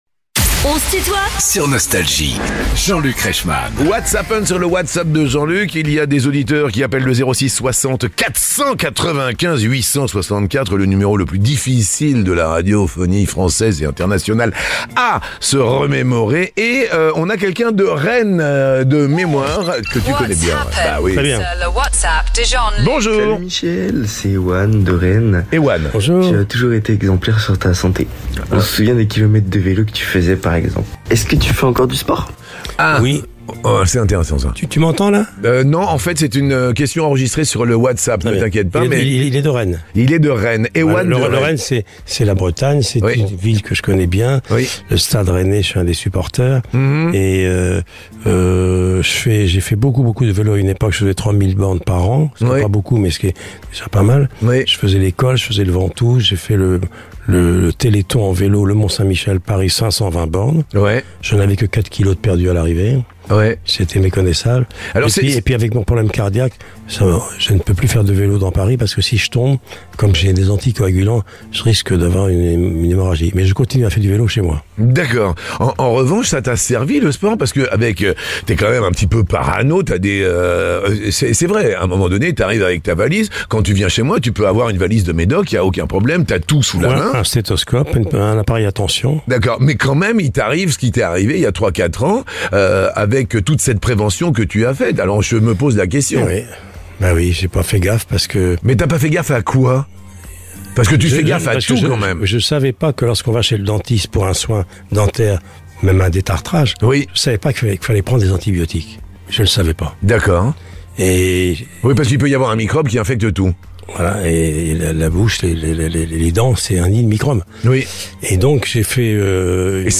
What's happen : Michel Drucker répond aux questions des auditeurs Nostalgie
Les interviews